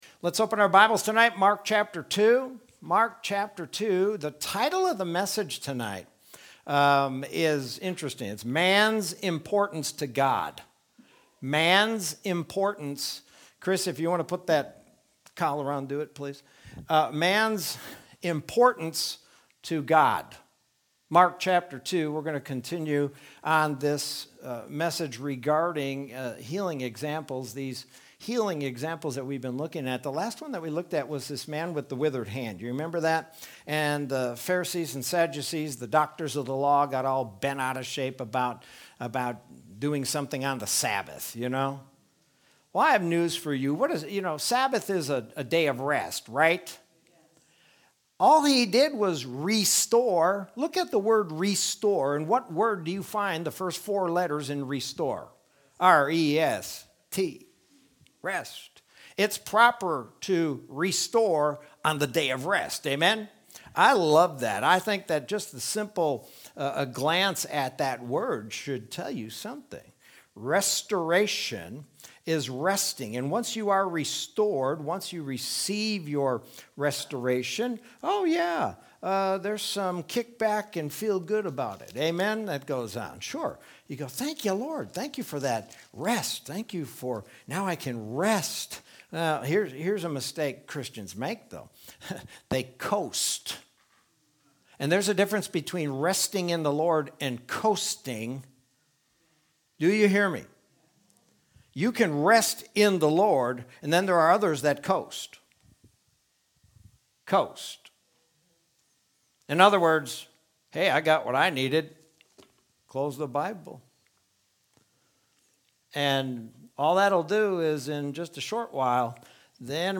Sermon from Wednesday, April 7th, 2021.